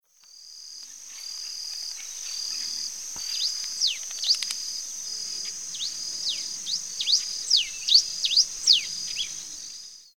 Black-and-rufous Warbling Finch (Poospiza nigrorufa)
Life Stage: Adult
Location or protected area: Delta del Paraná
Condition: Wild
Certainty: Observed, Recorded vocal